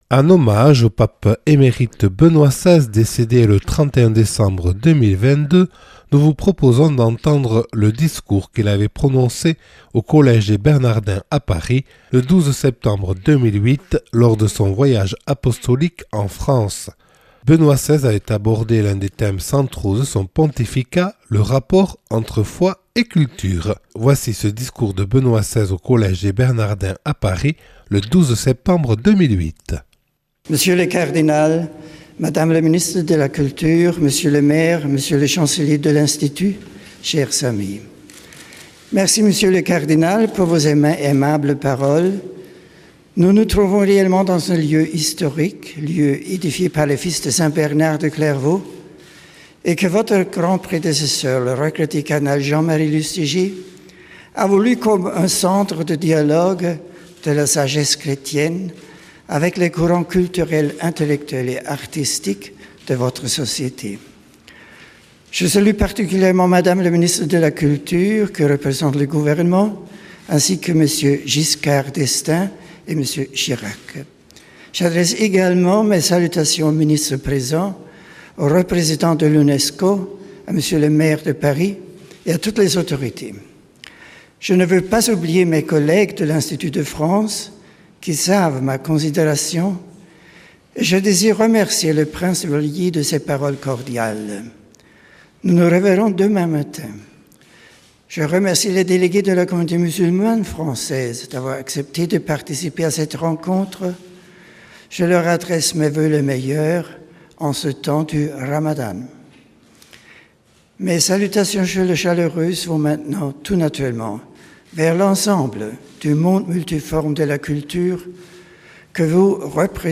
Discours de Benoît XVI au Collège des Bernardins à Paris